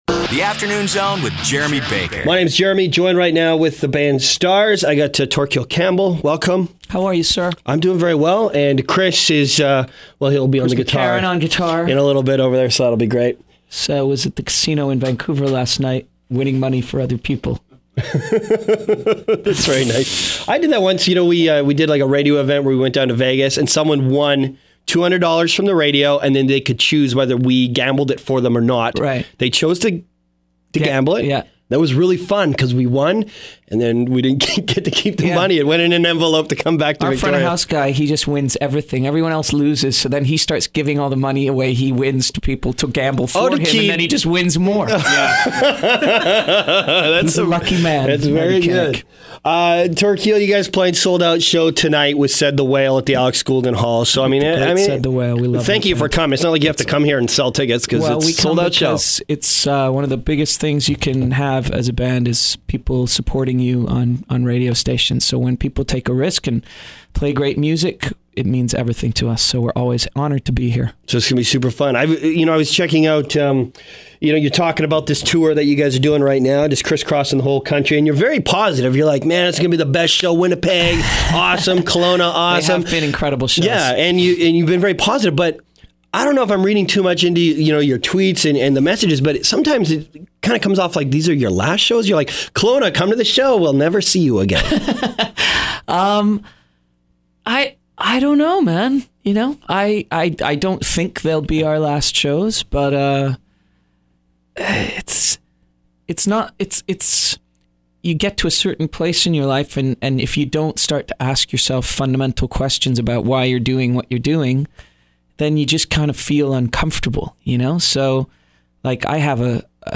A Conversation with Torquil Campbell of Stars
Stars frontman Torquil Campbell stopped by The Zone Afternoon Show on Friday.